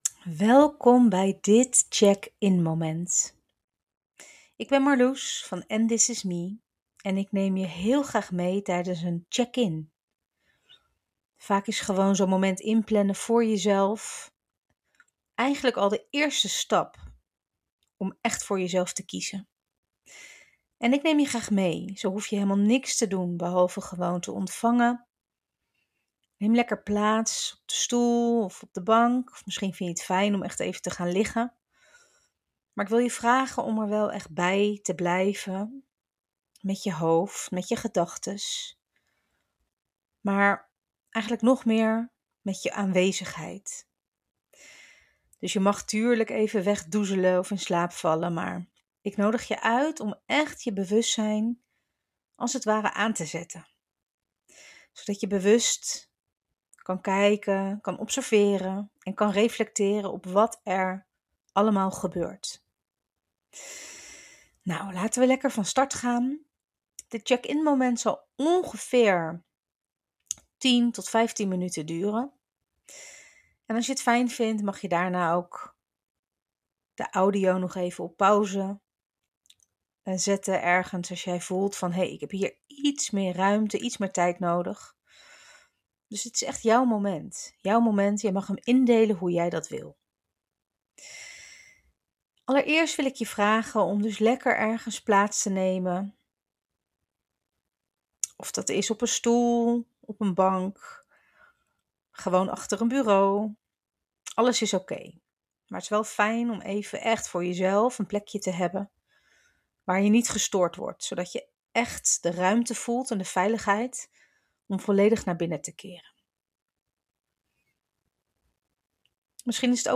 Ik hoop dat deze visualisatie je inspireert.